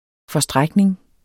Udtale [ fʌˈsdʁagneŋ ]